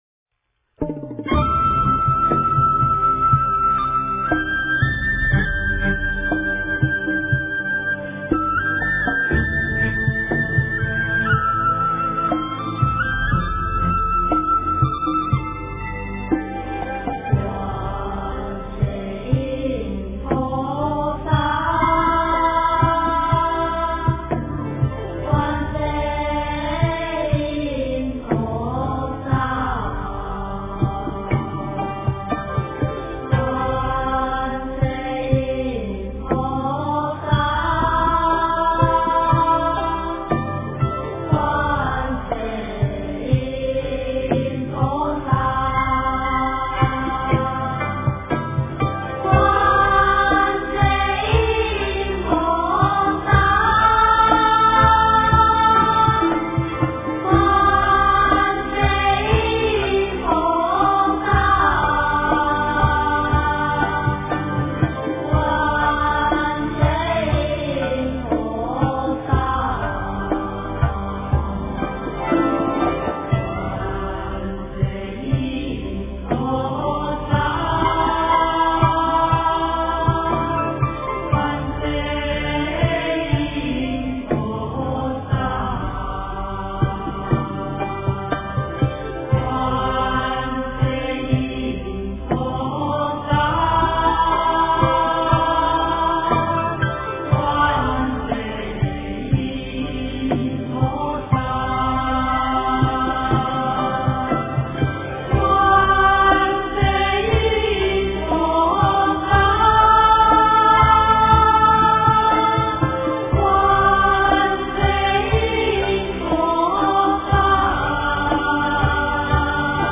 诵经
佛音 诵经 佛教音乐 返回列表 上一篇： 大慈大悲观世音菩萨 下一篇： 心经-付嘱 相关文章 林中散步--理查德克莱德曼 林中散步--理查德克莱德曼...